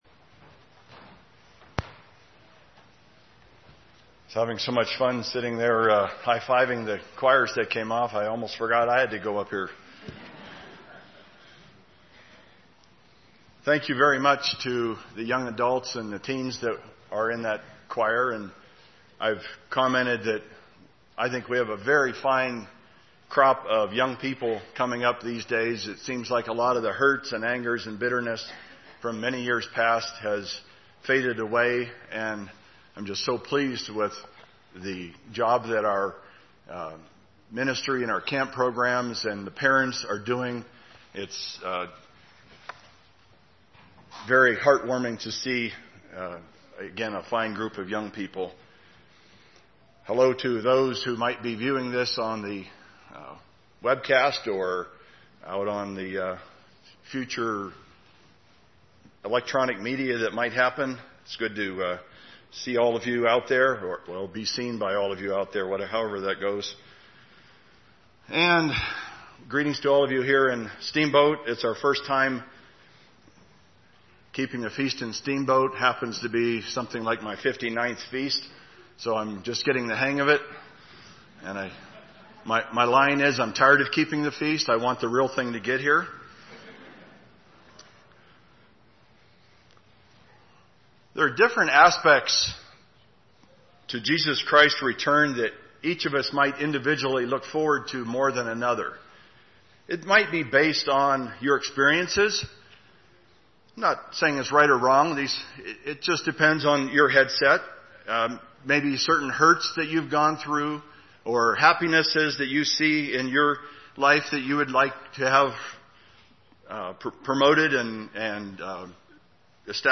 This sermon was given at the Steamboat Springs, Colorado 2016 Feast site.